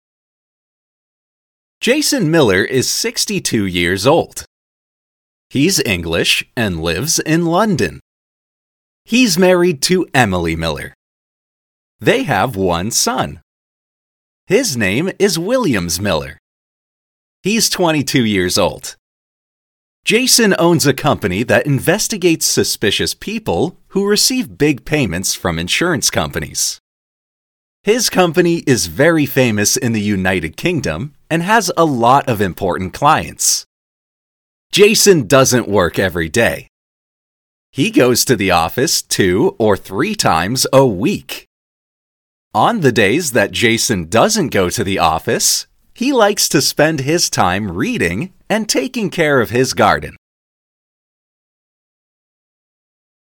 Lesson 21 - Shadowing